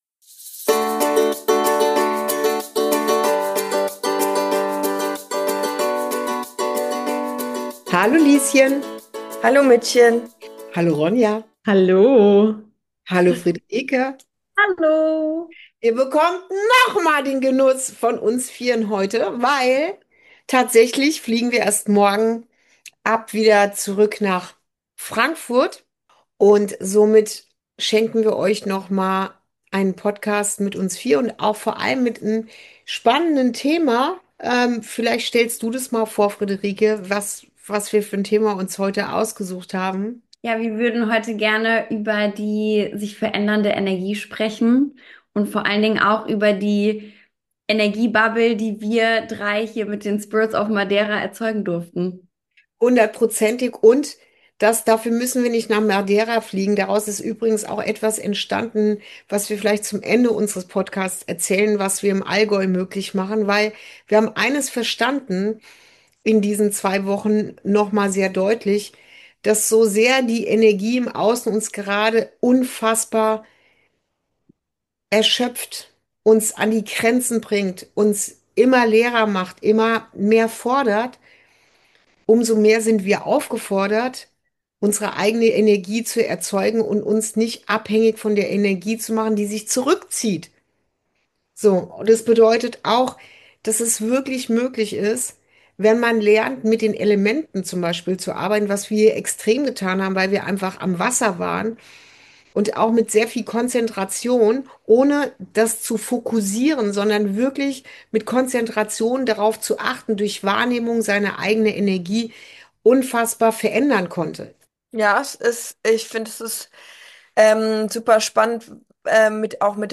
Ein Gespräch zwischen Mutter und Tochter